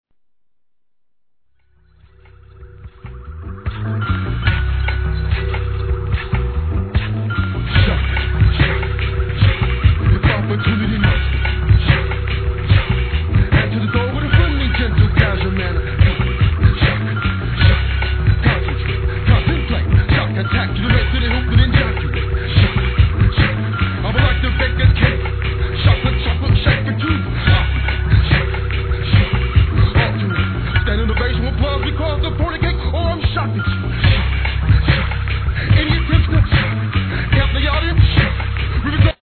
1. HIP HOP/R&B
jazzyなベースラインやおどろおどろしい上音、癖になるフロウ！